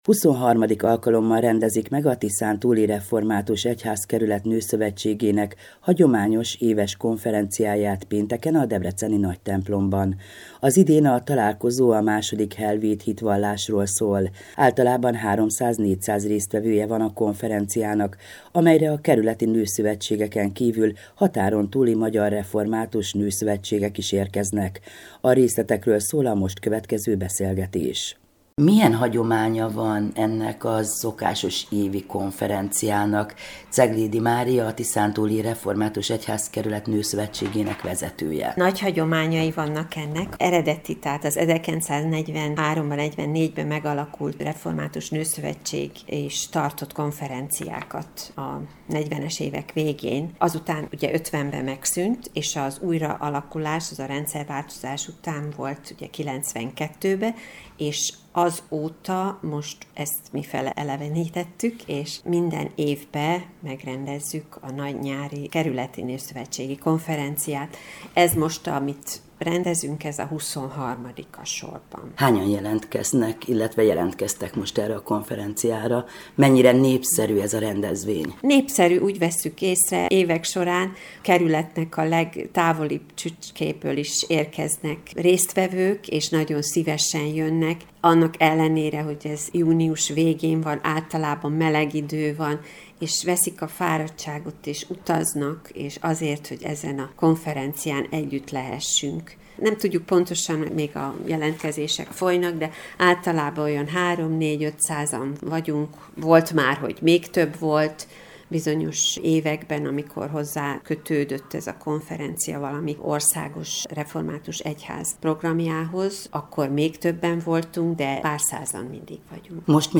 0701-noszov-konferencia.mp3